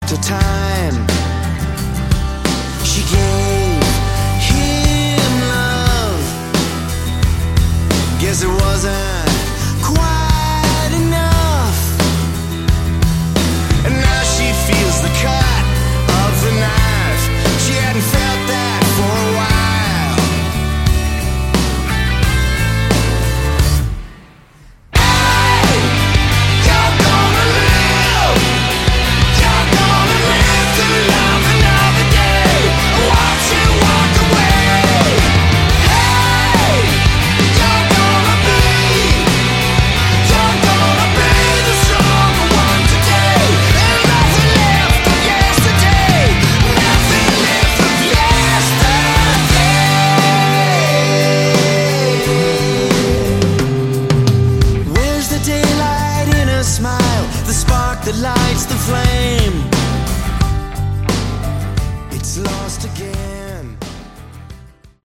Category: Melodic Hard Rock
drums, percussion, lead and backing vocals
keyboards, piano, backing vocals